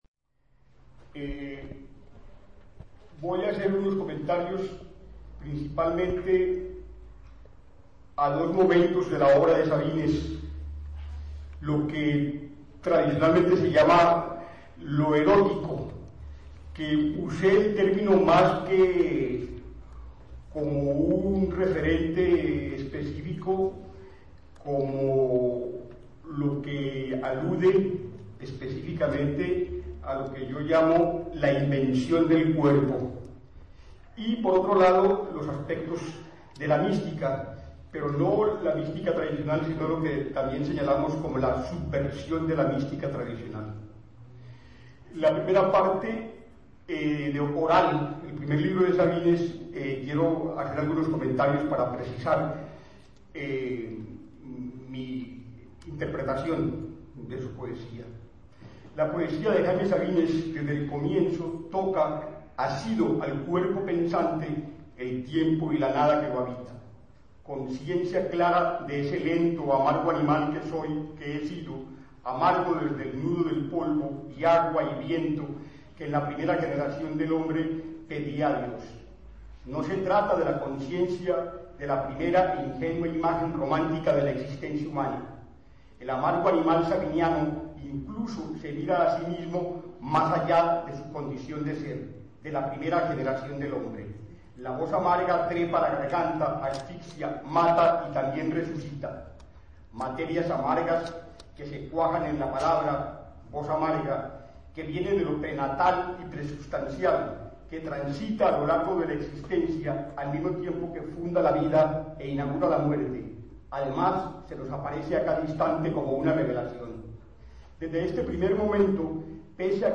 Conferencia
Lugar de la grabación: Auditorio del Centro Cultural de Chiapas Jaime Sabines Fecha: Sábado 24 de marzo del 2007.
Equipo: Minidisc Net MD-N707, micrófono de construcción casera (más info)